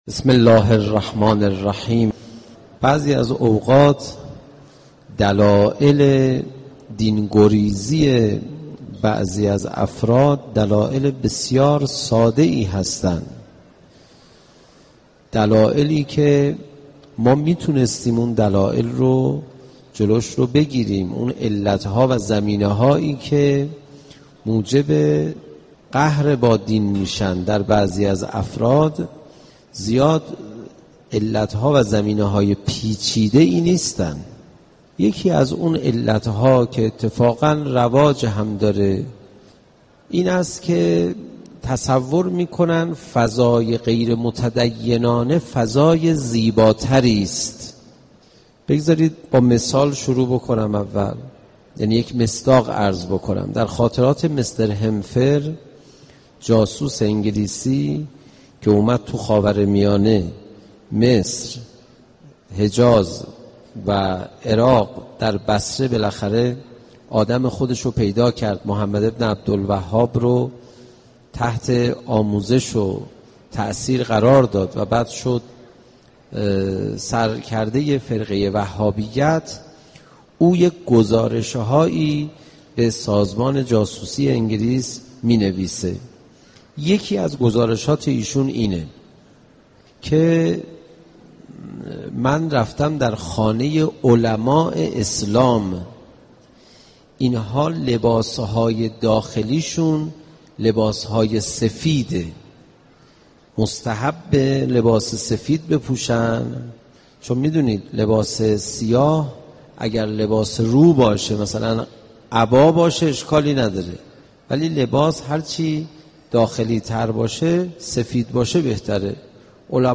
سخنرانی حجت الاسلام والمسلمین علیرضا پناهیان با موضوع زیبایی ایمان